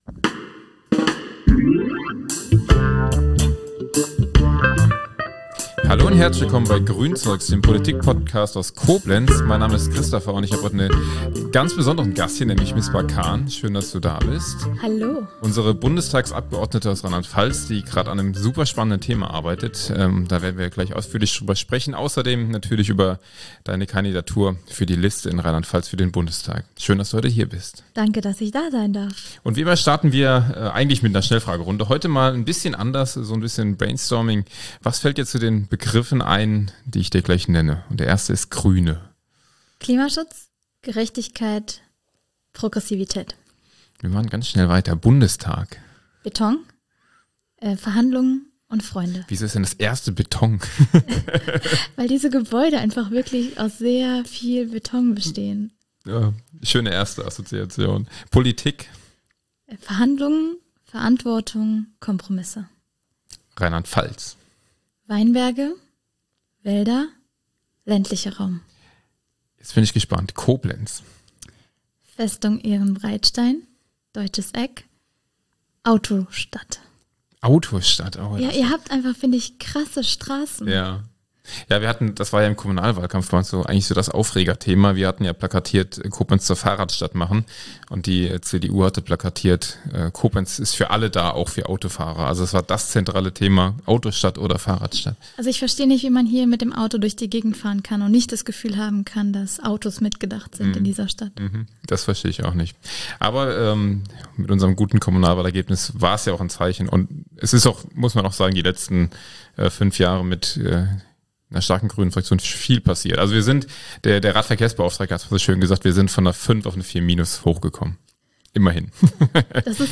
Misbah Khan, MdB zu Gast bei Grünzeugs ~ Grünzeugs Podcast